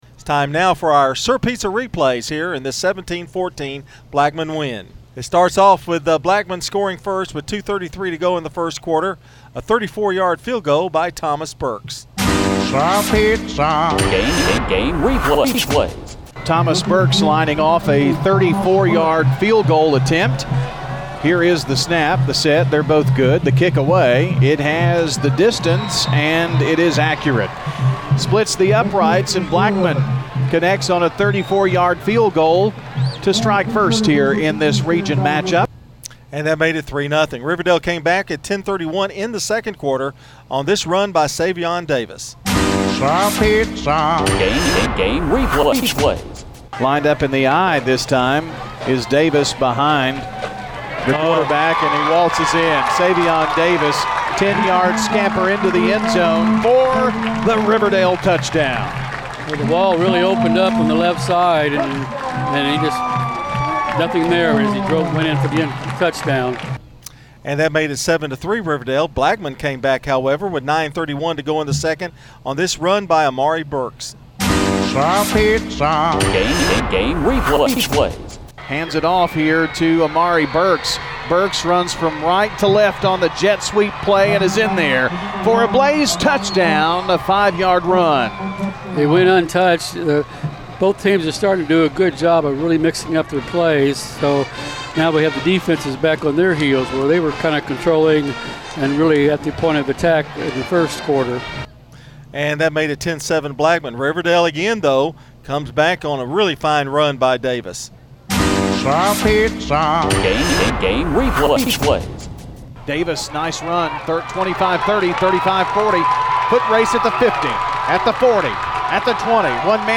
SIR PIZZA SCORING RECAP Details Published: 03 September 2016 Prev Next
BHS-RHS-Sir-Pizza-Game-Replays.mp3